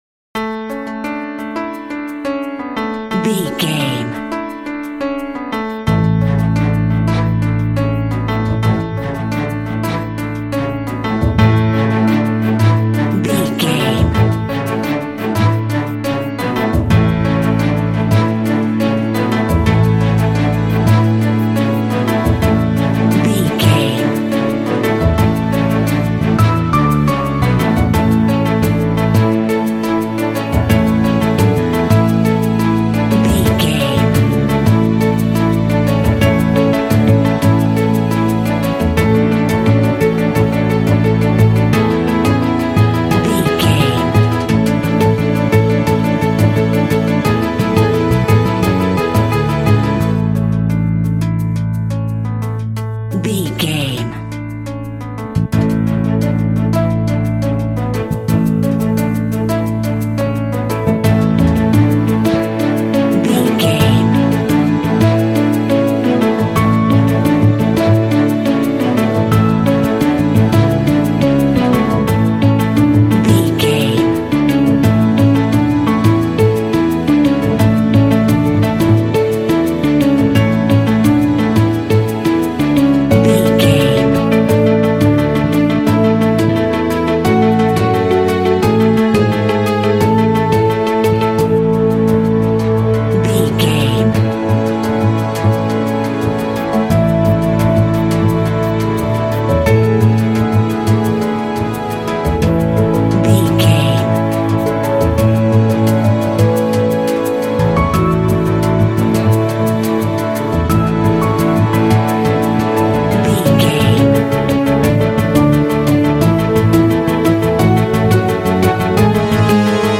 Uplifting
Ionian/Major
inspirational
soothing
piano
strings
percussion
cinematic
contemporary underscore